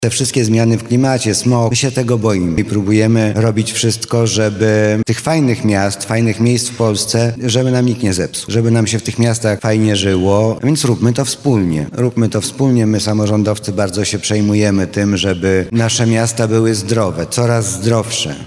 Mariusz Banach – mówi wiceprezydent Lublina Mariusz Banach.